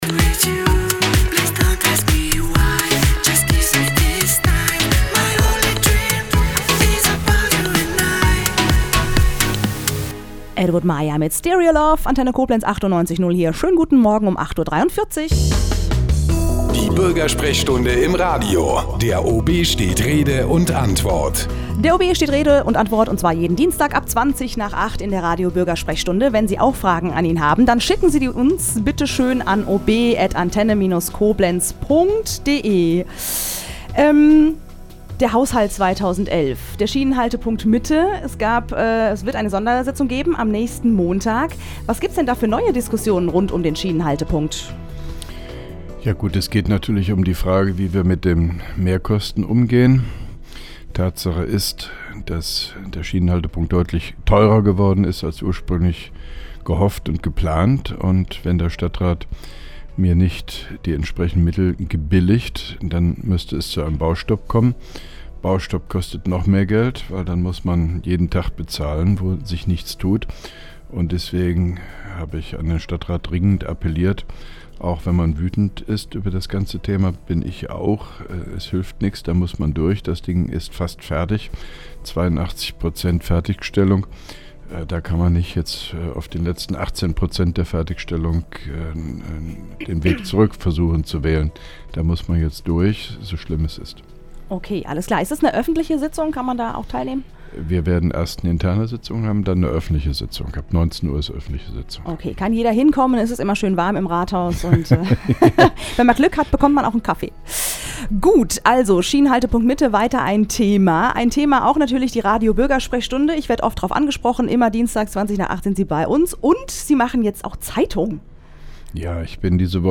(3) Koblenzer Radio-Bürgersprechstunde mit OB Hofmann-Göttig 11.01.2011